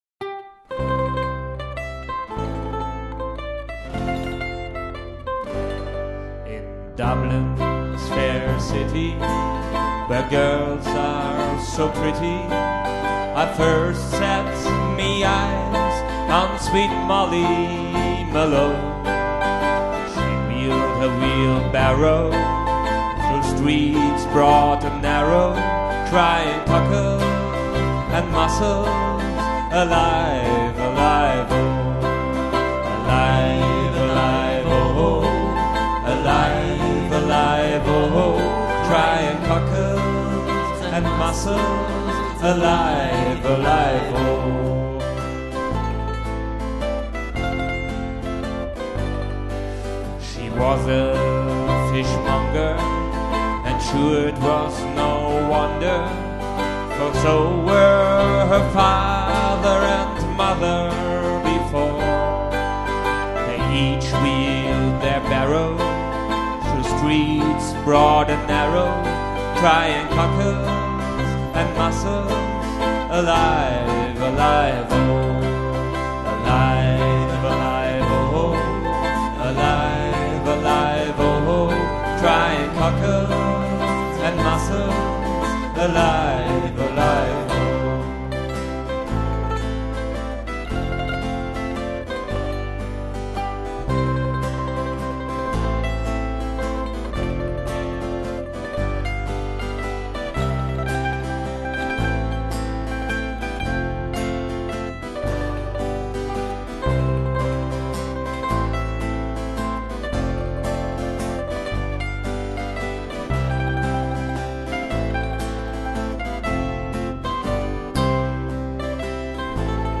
Molly Malone (ирландская народная)